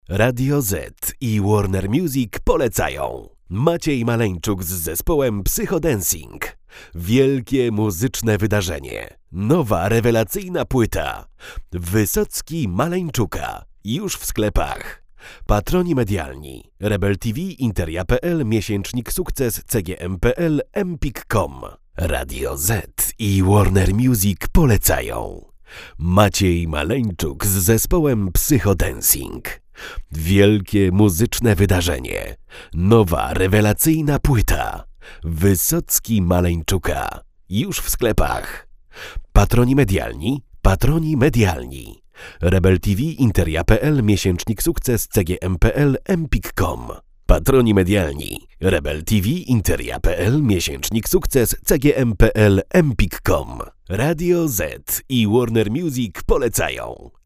Neumann TLM-103 + UAudio 710